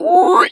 pig_2_hog_single_08.wav